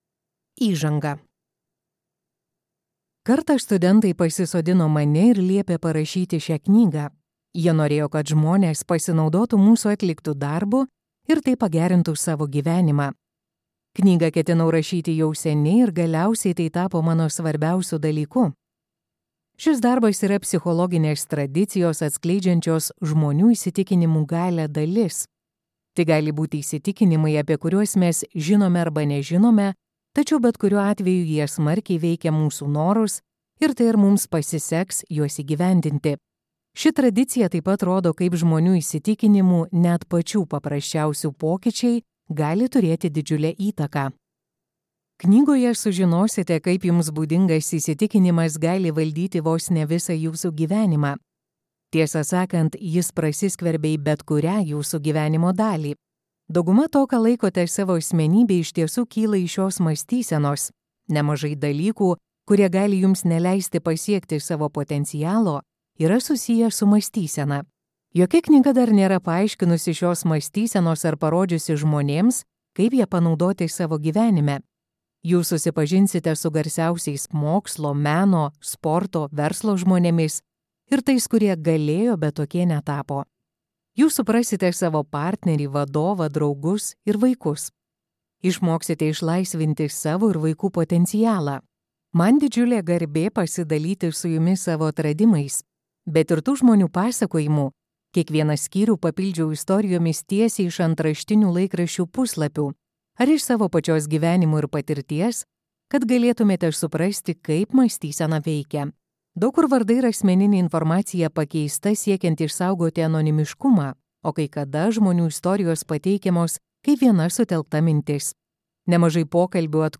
Sėkmės psichologija | Audioknygos | baltos lankos